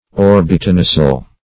Search Result for " orbitonasal" : The Collaborative International Dictionary of English v.0.48: Orbitonasal \Or`bi*to*na"sal\, a. [Orbit + nasal.]
orbitonasal.mp3